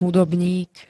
Zvukové nahrávky niektorých slov
rhnq-hudobnik.spx